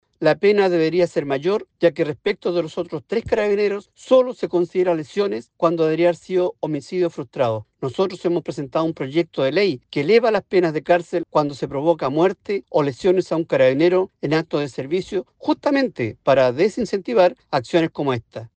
Otro diputado, Roberto Arroyo, del Partido Social Cristiano, dijo que frente a estas resoluciones es que decidió patrocinar una iniciativa legal para elevar las penas por este tipo de graves delitos.